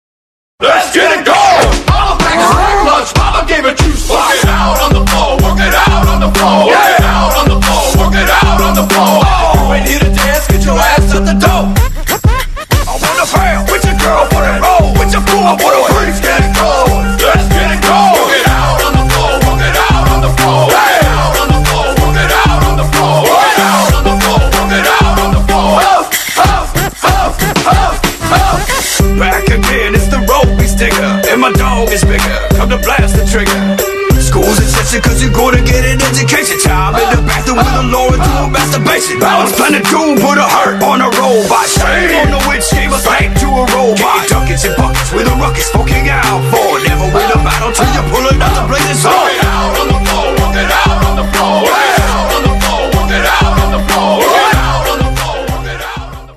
• Качество: 192, Stereo
Rap